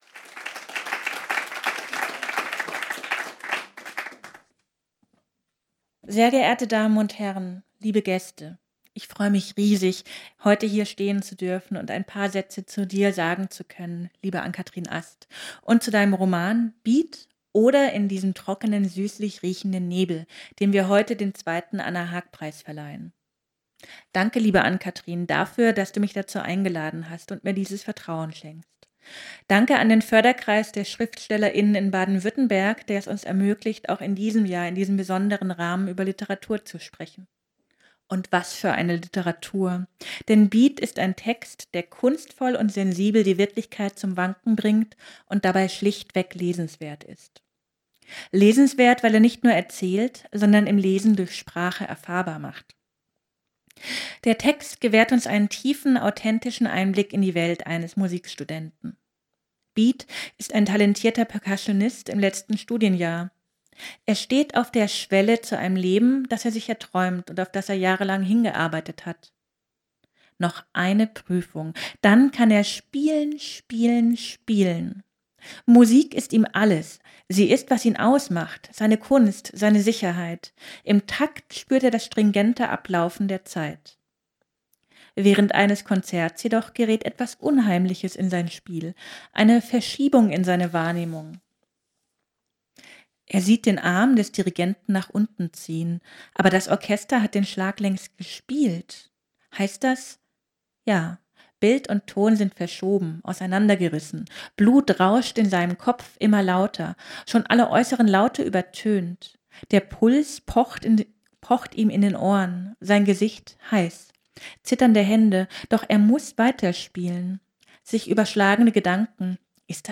Vorträge, Lesungen und Diskussionen zum Nachhören.